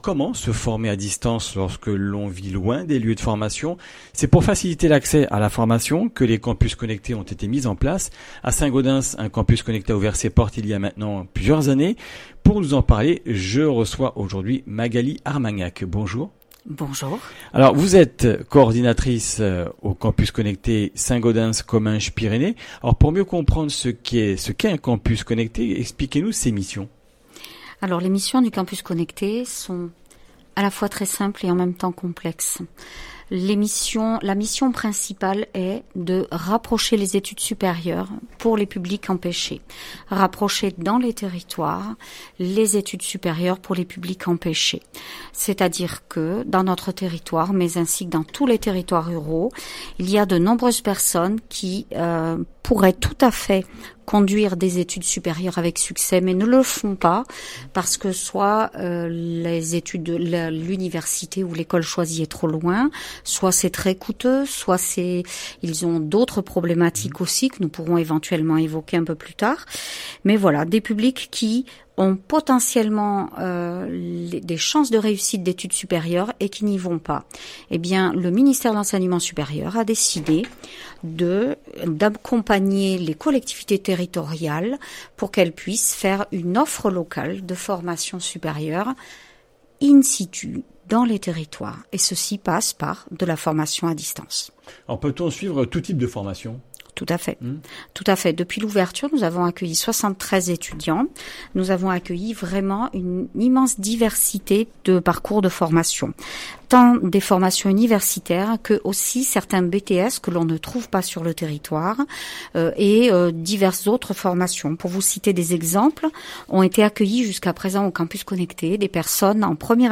le campus connecté de Saint-Gaudens pour suivre une formation à distance. Interview